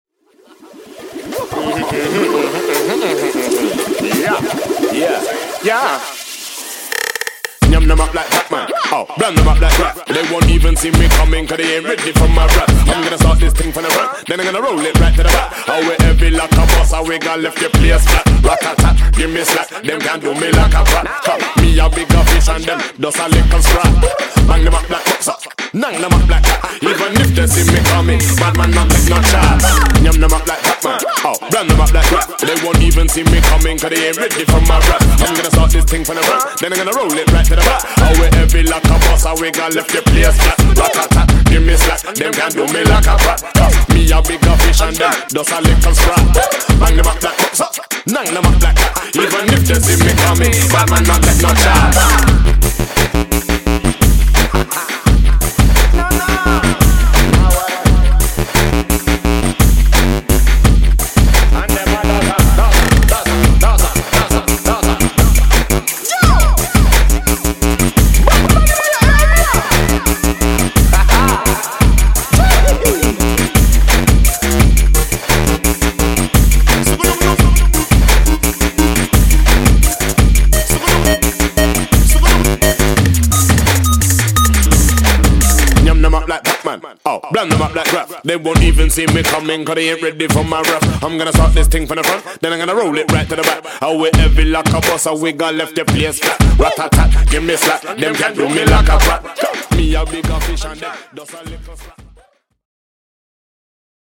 Styl: Dub/Dubstep, Drum'n'bass